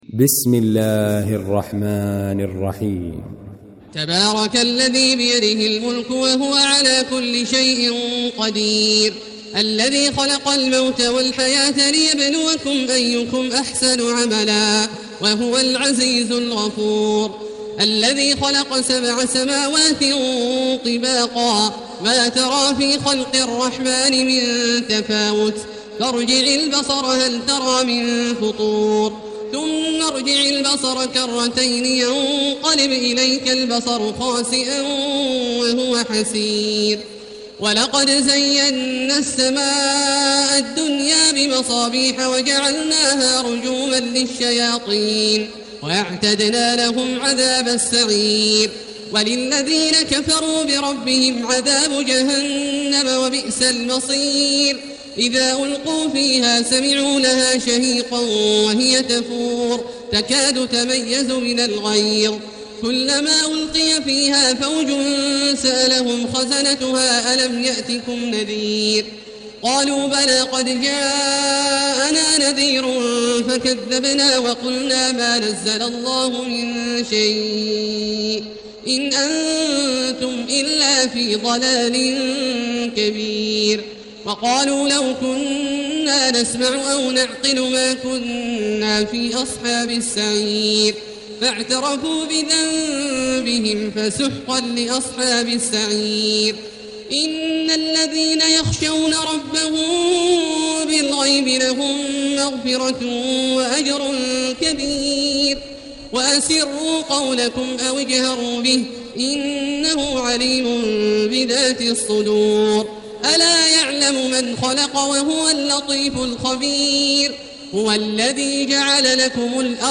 المكان: المسجد الحرام الشيخ: فضيلة الشيخ عبدالله الجهني فضيلة الشيخ عبدالله الجهني الملك